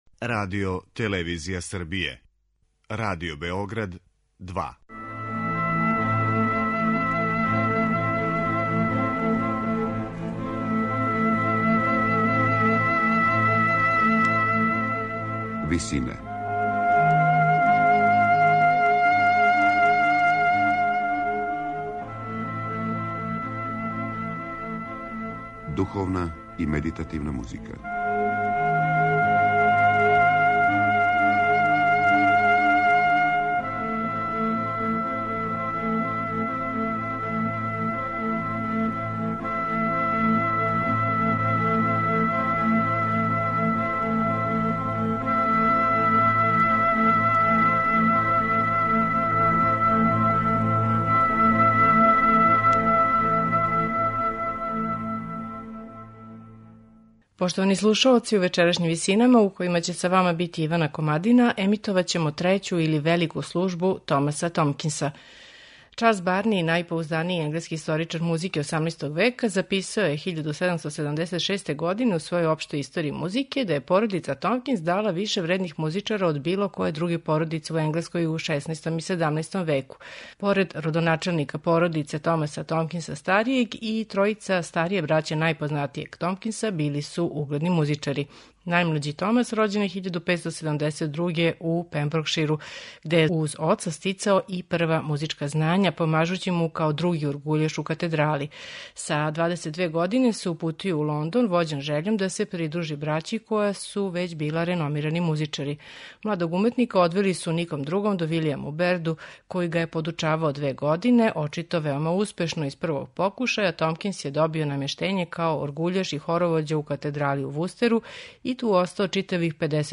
Чине га четири става, која се изводе у различитим приликама: Te Deum и Jubilate намењени су јутарњој служби, а Magnificat и Nunc dimitis вечерњој. По контрапунктском мајсторству и маштовитости у коришћењу и комбиновању гласова, Томкинс је овом Великом службом успео да надмаши свог великог претходника Вилијама Берда.
Велику службу Томаса Томкинса слушаћете у интерпретацији хора The Tallis Schoolars , под управом Питера Филипса.